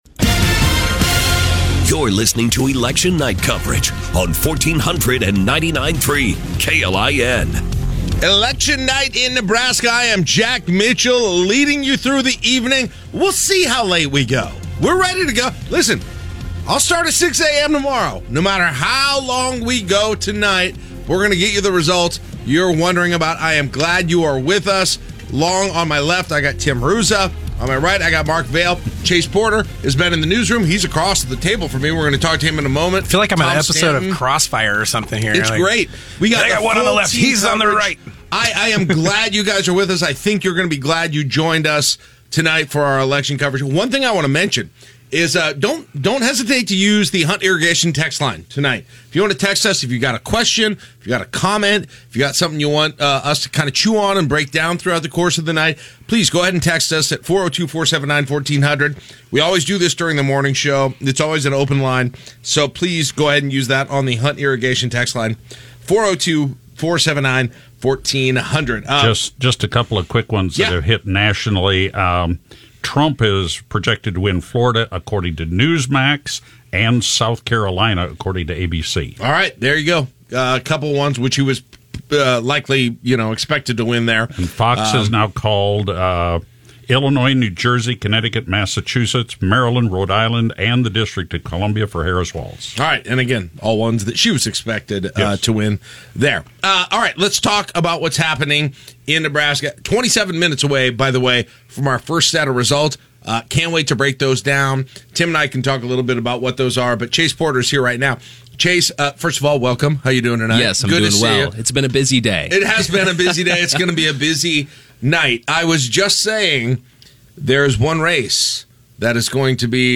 This honor recognizes our live Election Night coverage from November 5, 2024.